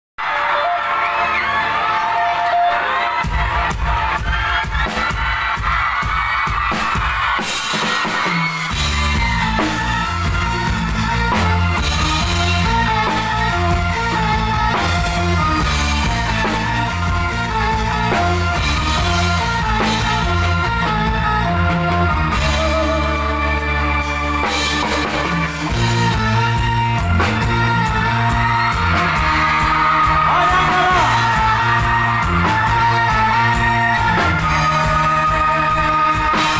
campursari